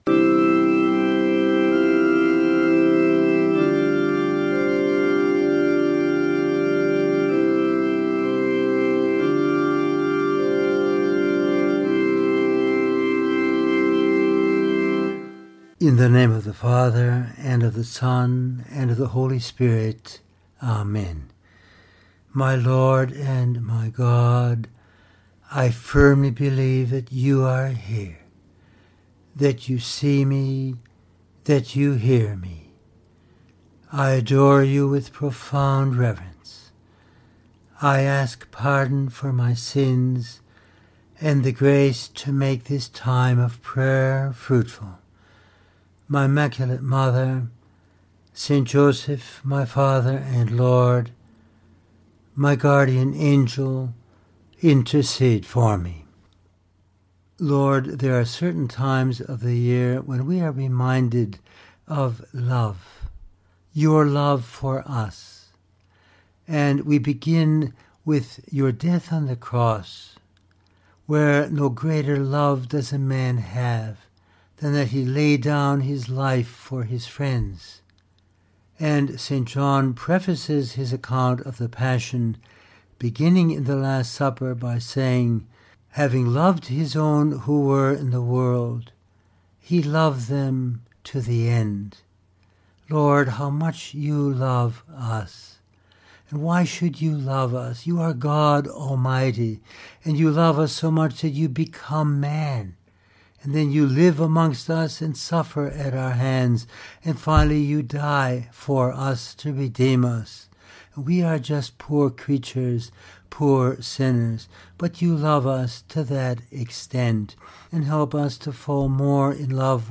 Our Lord loved us “to the end” and he gives us the New Commandment to love one another as he has loved us. In this meditation we consider how: